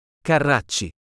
Captions English Audio pronunciation file.
It-Carracci.wav